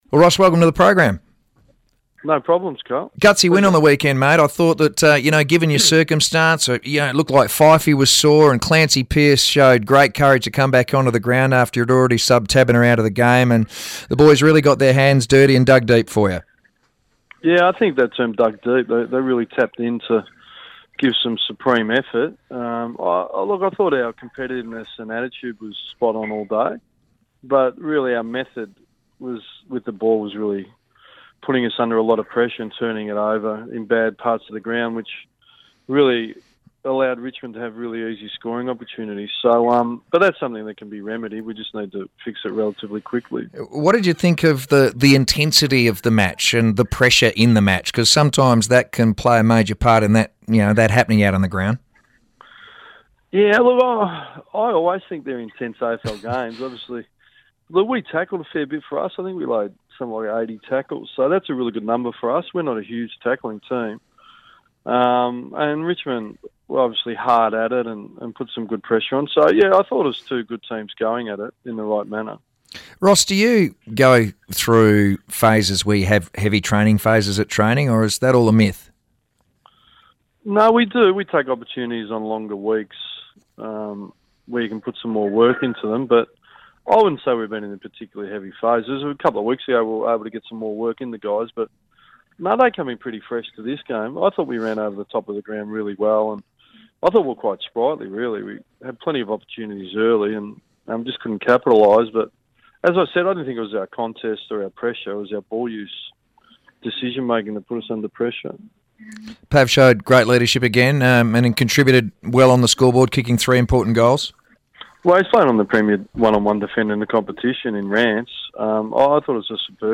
Ross Lyon speaks to Sports Today following the win over Richmond